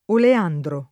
oleandro [ ole # ndro ]